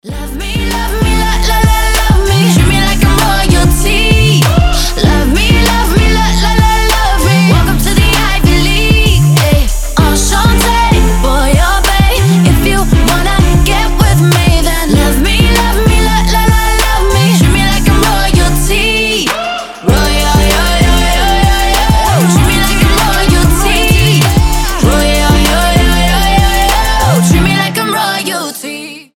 • Качество: 320, Stereo
поп
громкие
женский вокал